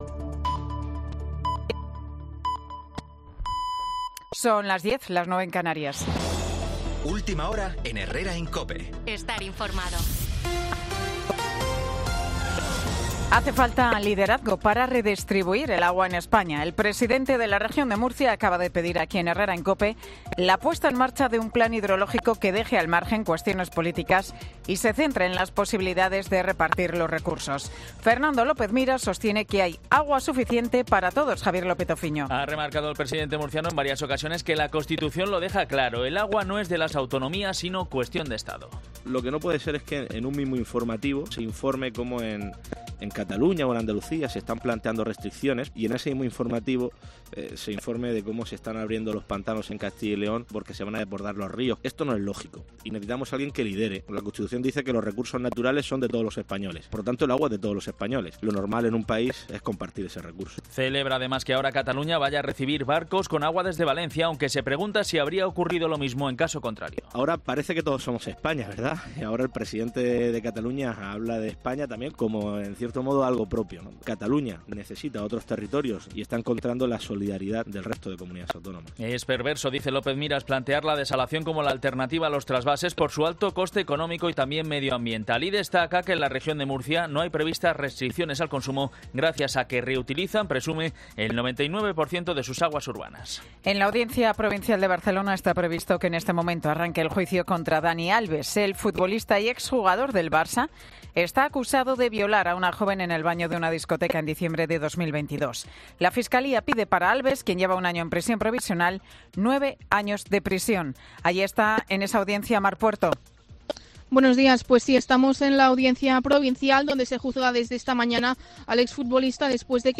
Boletín de Noticias de COPE del 5 de febrero del 2024 a las 10 horas